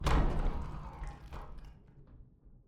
Commotion11.ogg